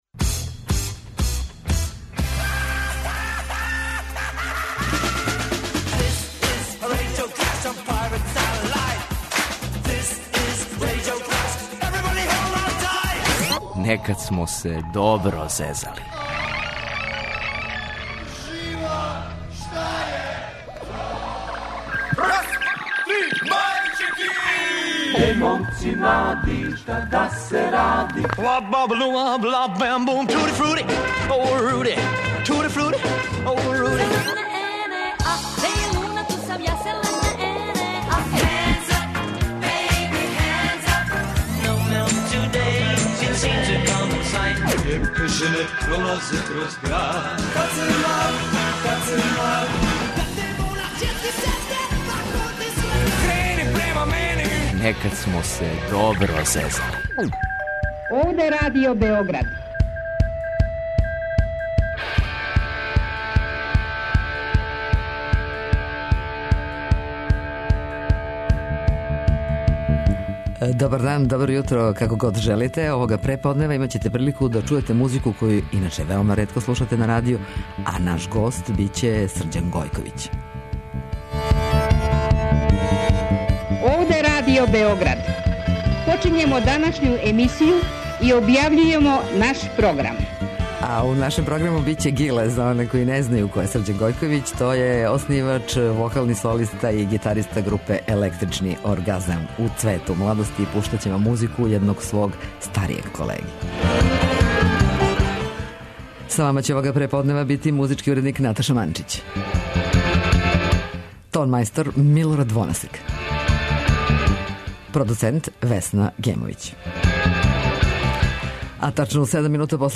Наш гост је Срђан Гојковић-Гиле, оснивач и фронтмен групе 'Електрични оргазам'.